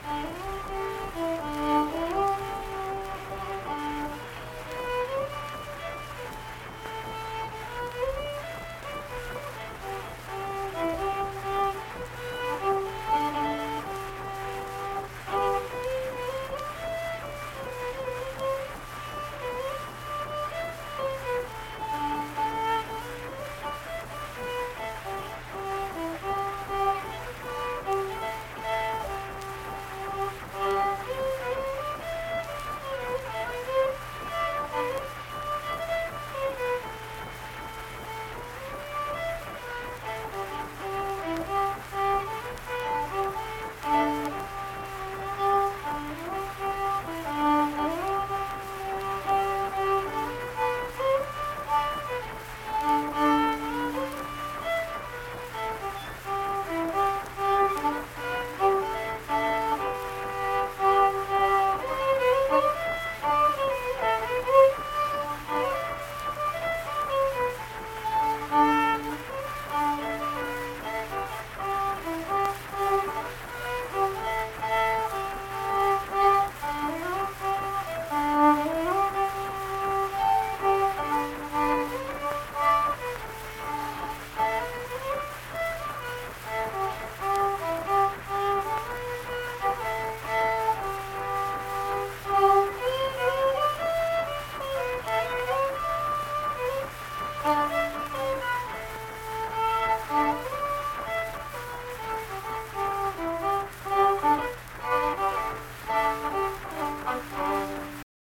Unaccompanied fiddle music performance
Verse-refrain 3(1).
Instrumental Music
Fiddle